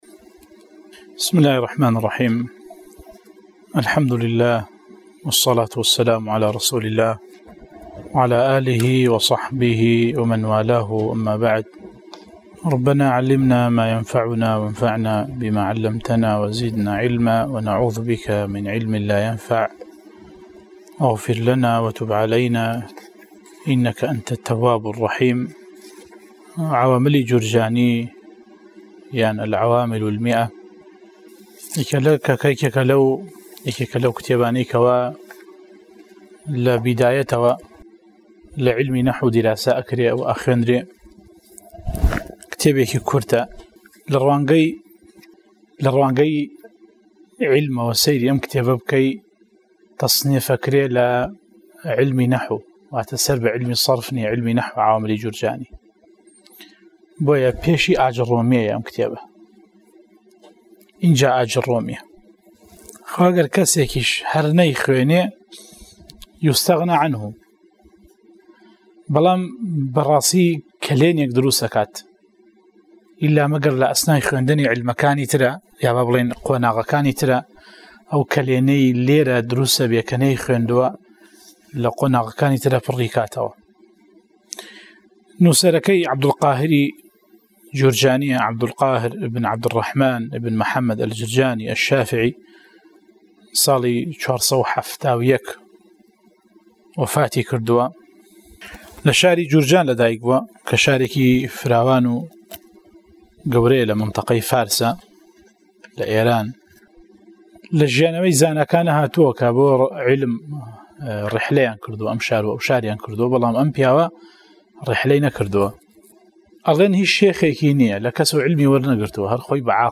01 ـ شەرحی العوامل المائة، (عوامل الجرجانی) وانەی دەنگی